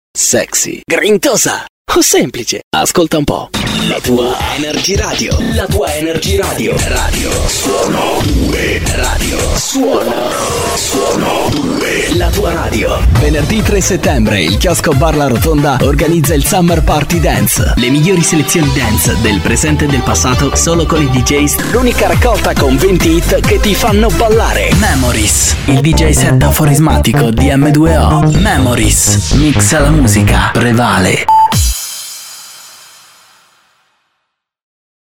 Italian voice talent.
Kein Dialekt
Sprechprobe: Werbung (Muttersprache):